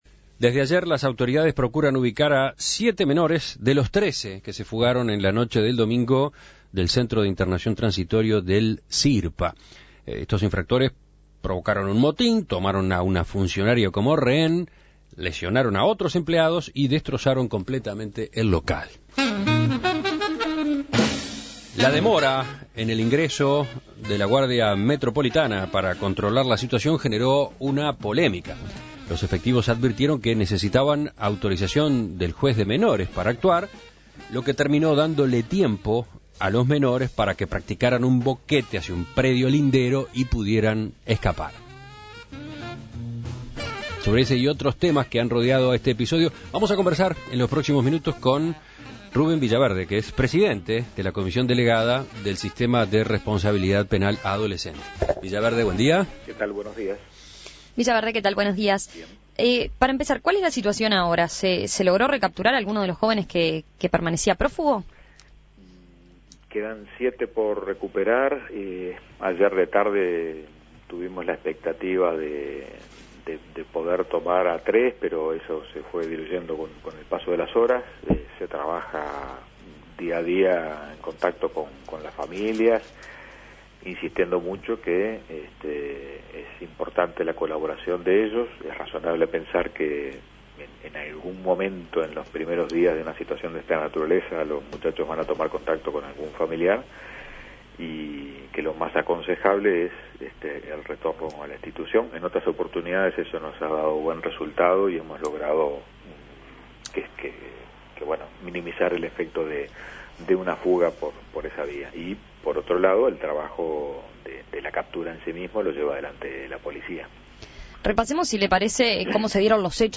Las autoridades siguen buscando a los menores que se fugaron del centro de internación transitoria del Sistema de Responsabilidad Penal Adolescente (Sirpa) el domingo por la noche. La situación no se pudo controlar debido a la demora en el ingreso de la Guardia Republicana, que no tenía la autorización del juez de menores. Para conocer más sobre este incidente En Perspectiva dialogó con el presidente del Sirpa, Ruben Villaverde, quien sostuvo que hay que "ajustar" los tiempos de estos procedimientos para que no vuelva a darse esa diferencia de minutos que permitió que los menores escaparan.